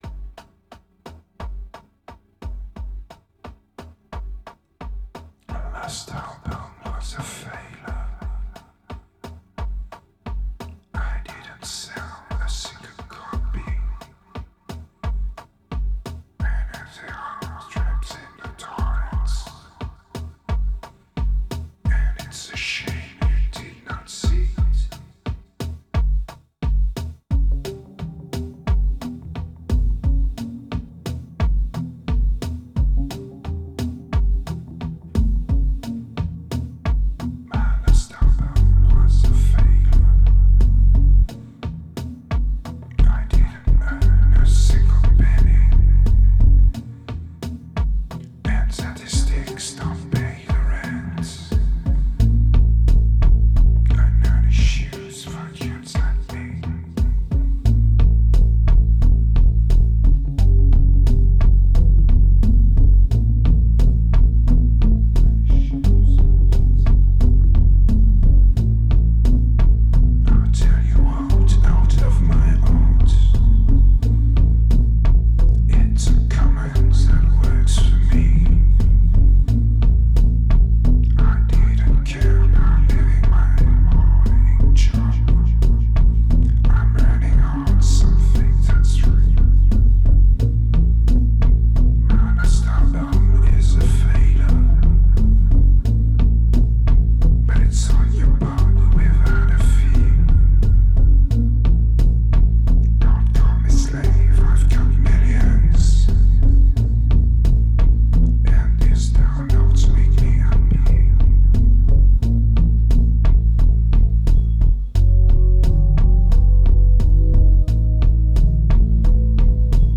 2292📈 - 80%🤔 - 88BPM🔊 - 2010-09-12📅 - 469🌟